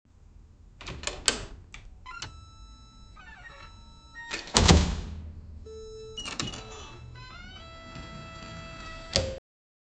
register-and-door-opening-3bhoy323.wav